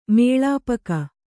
♪ mēḷāpaka